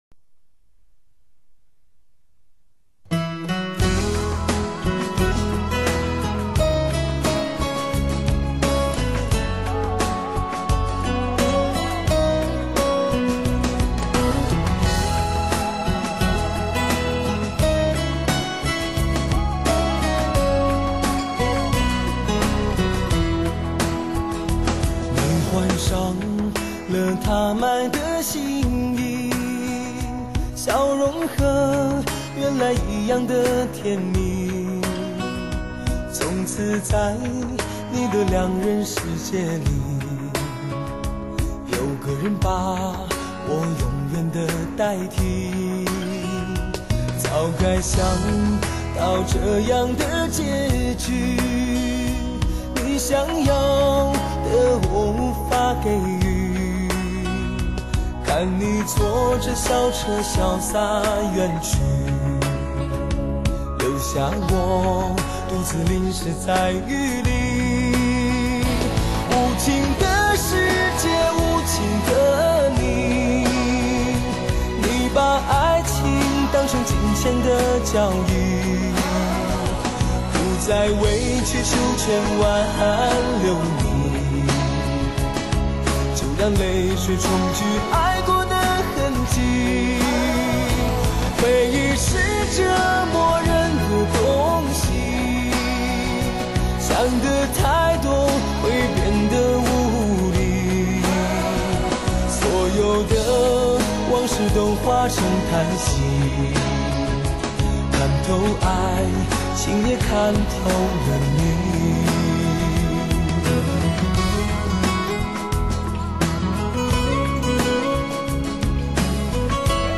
正宗缠绵彻骨情歌